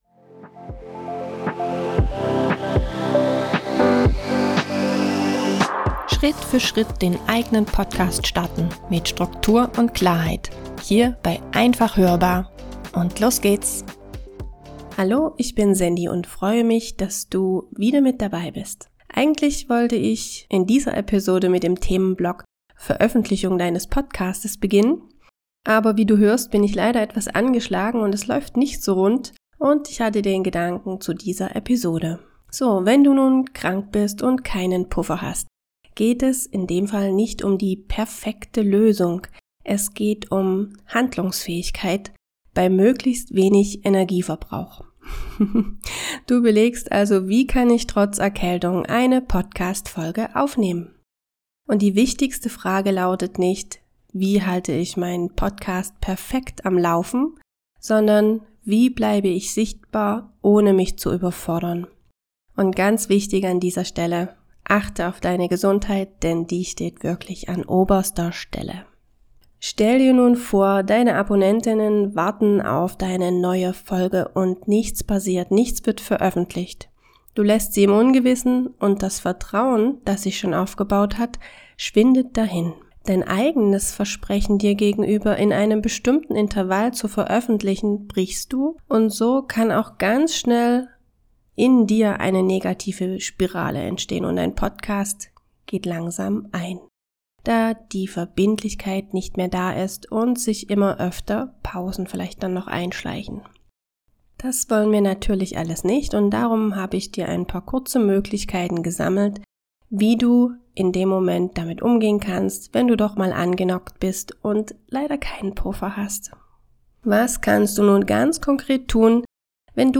Ich bin selbst gerade krank und nutze genau diese Situation, um ehrlich darüber zu sprechen, was du tun kannst, wenn du keinen Puffer hast, deine Stimme nicht richtig mitmacht oder dir schlicht die Energie fehlt. Du erfährst welche Möglichkeiten du hast, handlungsfähig zu bleiben, ohne dich zu überfordern.